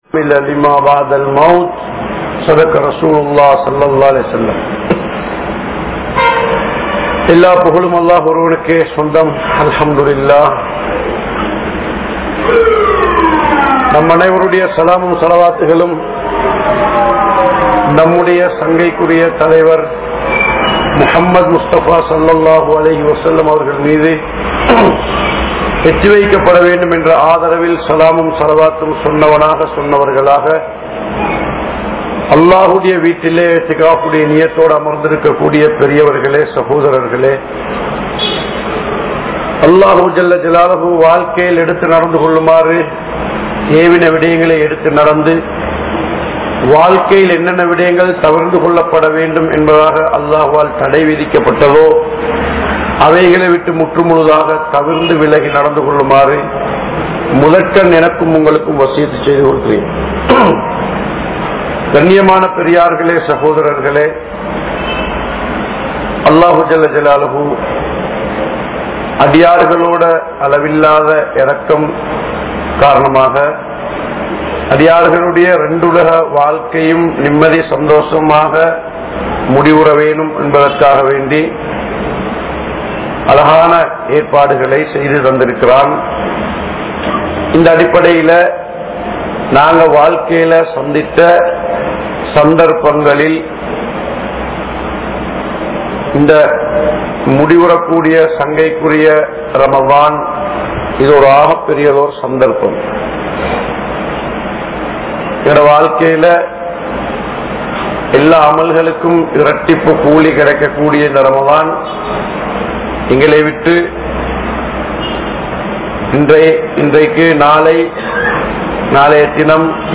Ramalan Eatpaduththiya Maattram Enna? (ரமழான் ஏற்படுத்திய மாற்றம் என்ன?) | Audio Bayans | All Ceylon Muslim Youth Community | Addalaichenai
Saliheen Jumua Masjidh